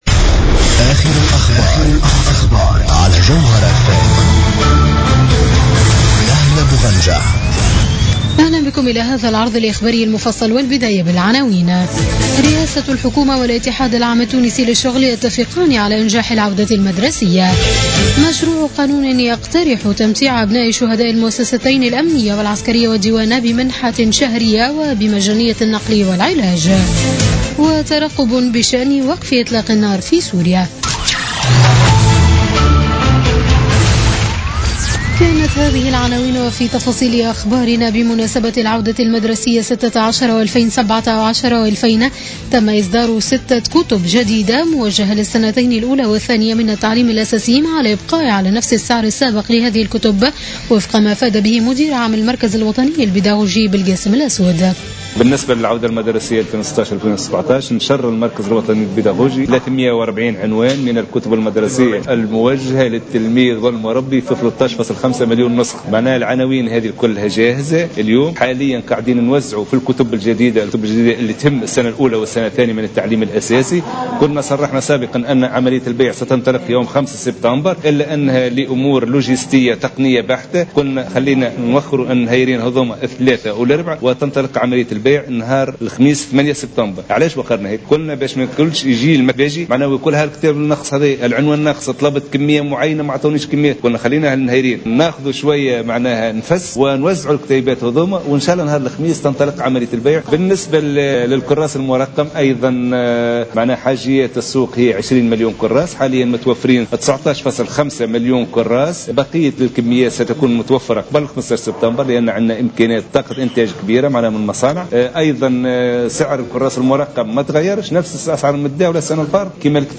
Journal Info 00h00 du mercredi 7 septembre 2016